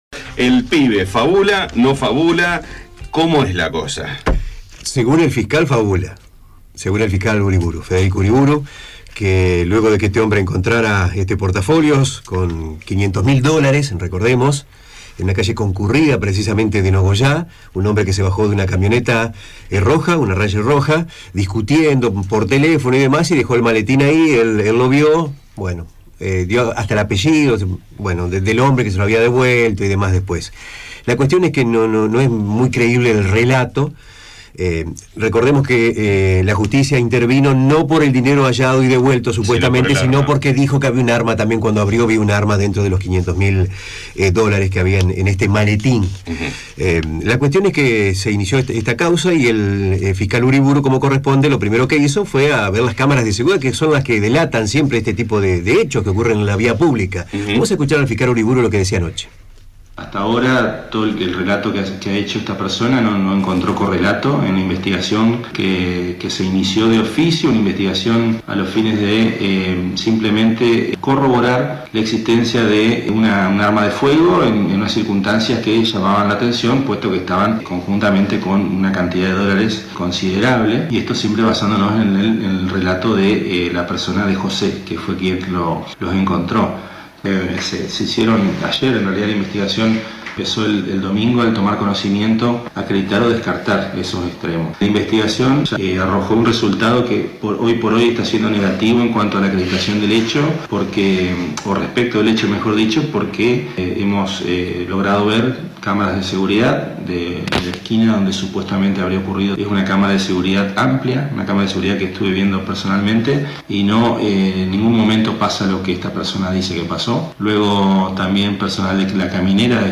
Aquí la palabra del fiscal Federico Uriburu: